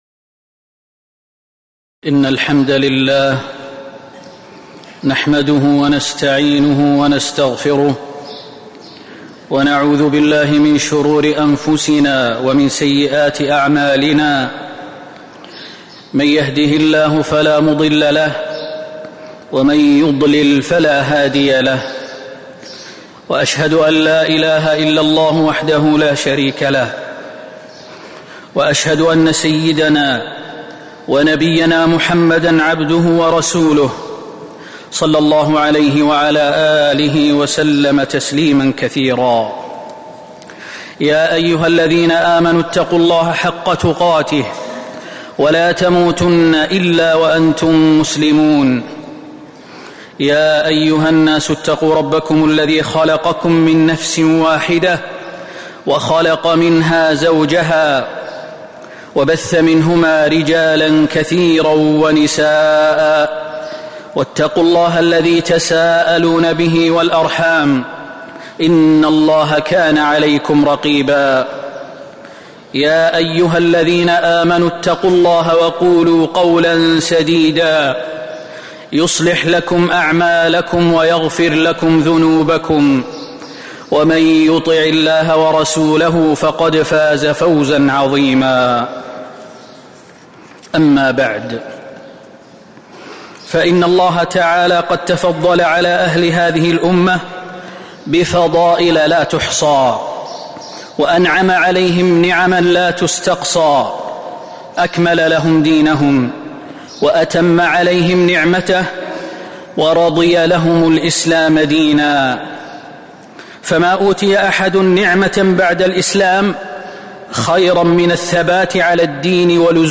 تاريخ النشر ١٣ شوال ١٤٤٦ هـ المكان: المسجد النبوي الشيخ: فضيلة الشيخ د. خالد بن سليمان المهنا فضيلة الشيخ د. خالد بن سليمان المهنا الثبات حتى الممات